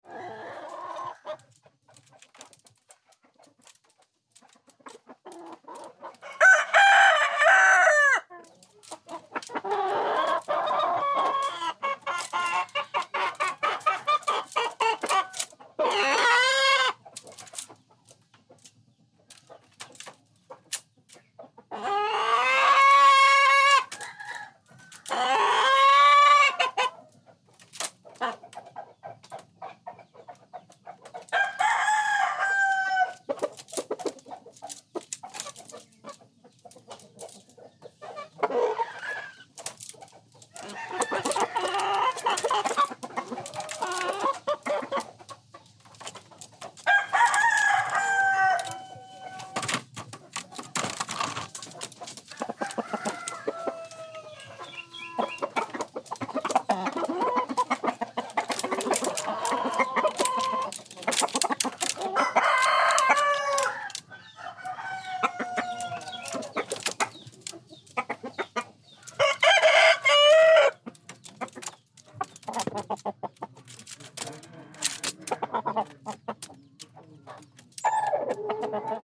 دانلود صدای مرغ و خروس در لانه و پرچین از ساعد نیوز با لینک مستقیم و کیفیت بالا
جلوه های صوتی